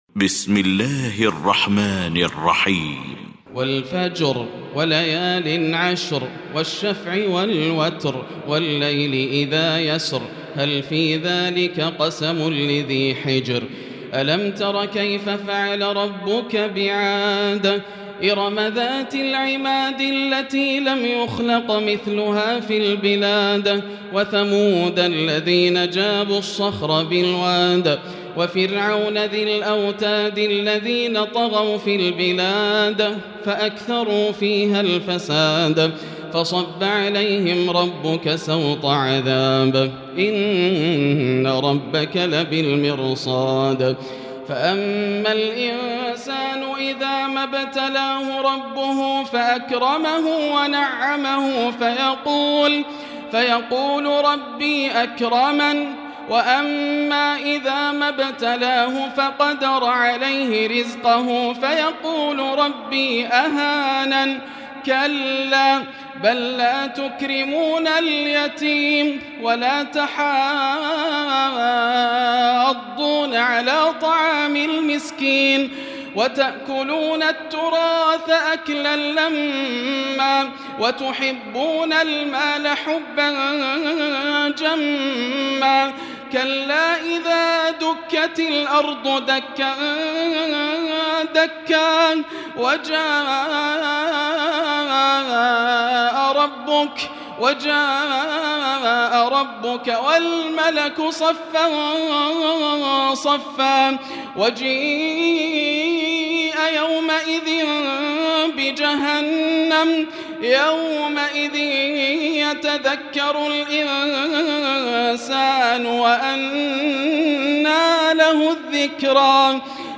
المكان: المسجد الحرام الشيخ: فضيلة الشيخ ياسر الدوسري فضيلة الشيخ ياسر الدوسري الفجر